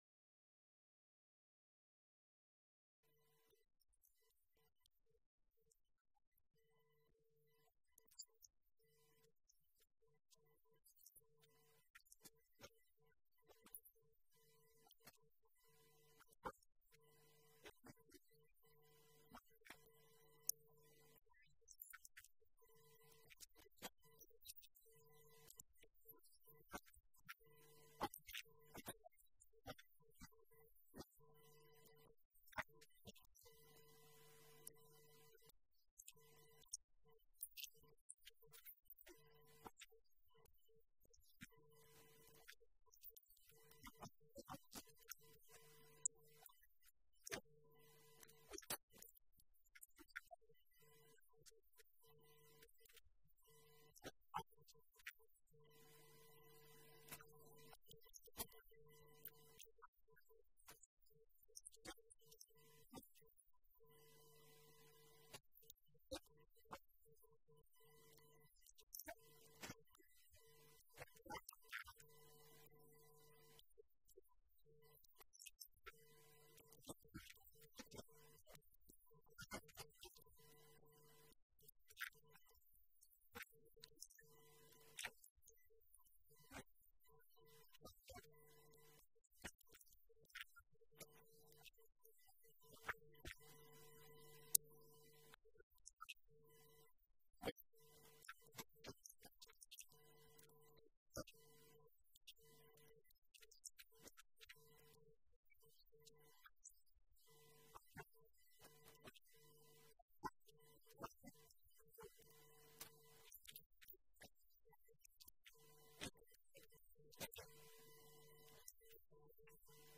This was the opening keynote of the day at Artemis London 2025, our fourth catastrophe bond and insurance-linked securities (ILS) conference in the City of London, UK held on September 2nd 2025.